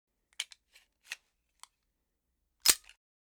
Bullet In 4.wav